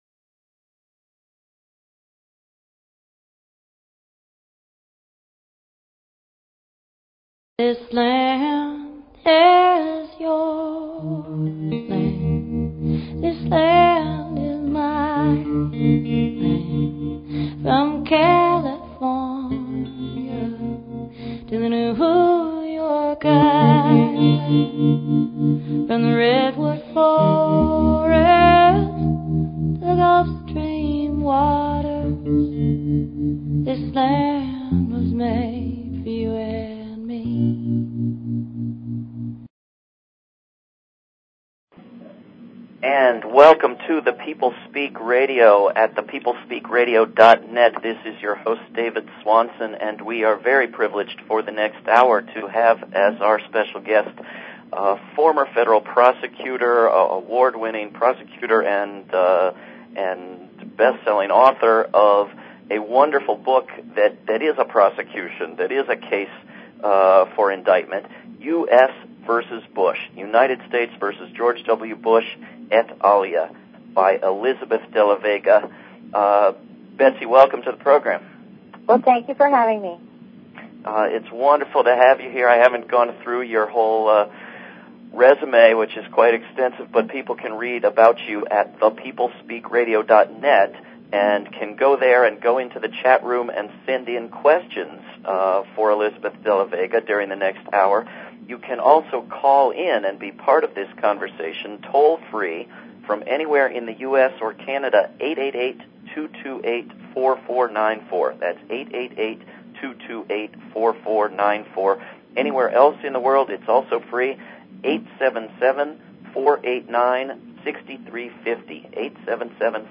Talk Show Episode, Audio Podcast, The_People_Speak and Courtesy of BBS Radio on , show guests , about , categorized as
The show features a guest interview from any number of realms of interest (entertainment, science, philosophy, healing, spirituality, activism, politics, literature, etc.).
The radio show name, The People Speak, is based on the idea of allowing our audience - the People - a chance to interact with the guests during the hour, and we take phone or text questions from them during the interview.